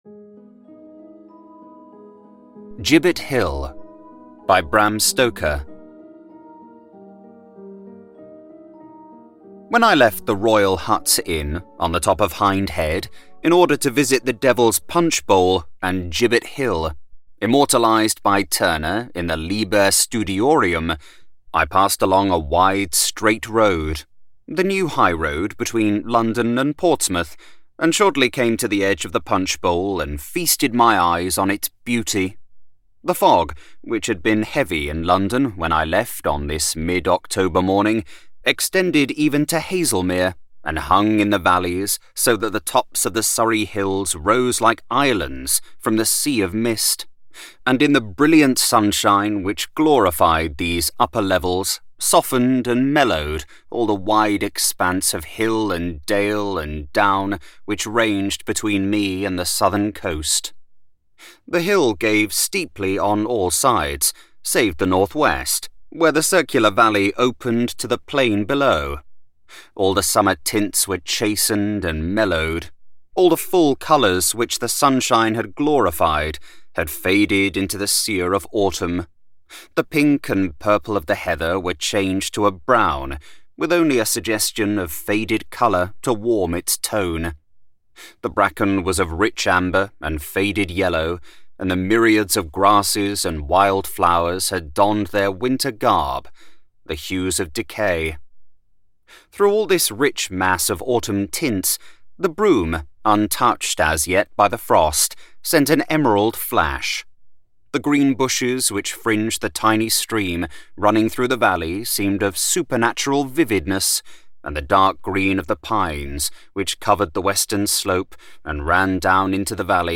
A Christmas Tree by Charles Dickens - Heartwarming Holiday Audiobook